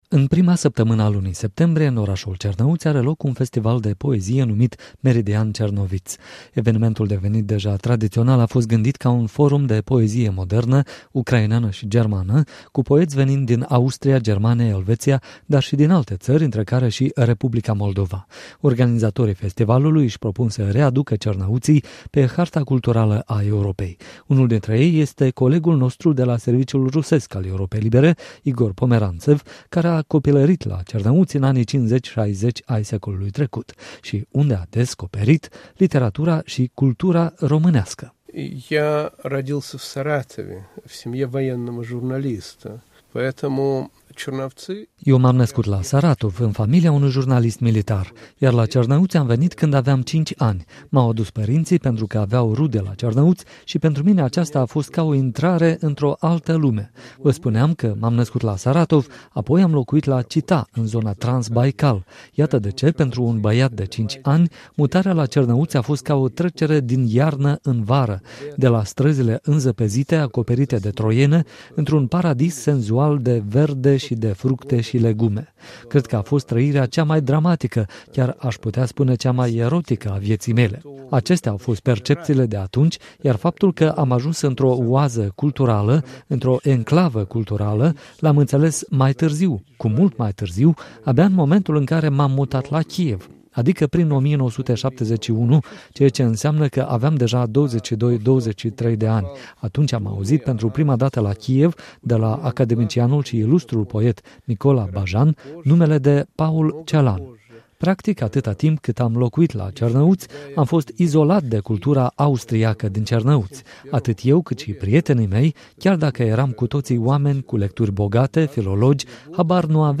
Interviu cu scriitorul Igor Pomeranţev